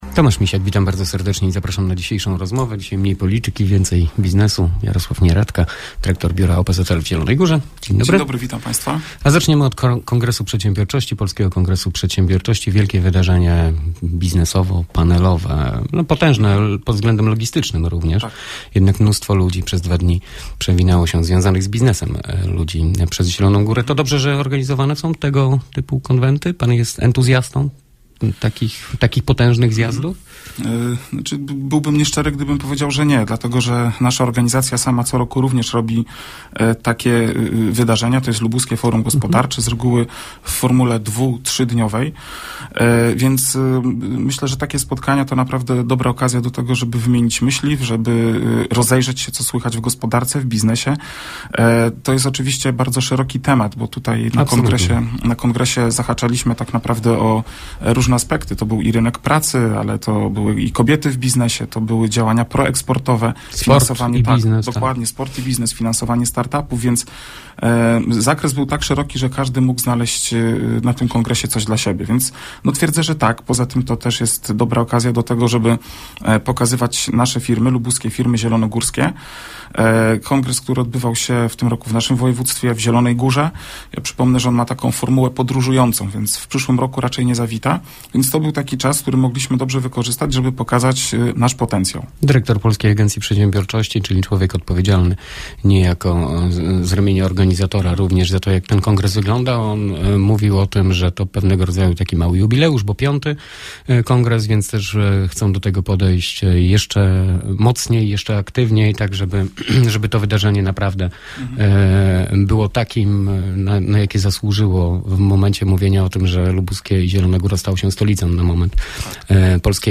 ROZMOWA NA 96 FM: Lubuskie nieatrakcyjne dla inwestorów? Co z kopalnią miedzi?